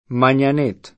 vai all'elenco alfabetico delle voci ingrandisci il carattere 100% rimpicciolisci il carattere stampa invia tramite posta elettronica codividi su Facebook Mañanet [sp. man’an $ t ] (italianizz. Magnanet [ man’n’an $ t ]) cogn.